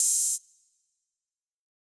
Open Hat [1].wav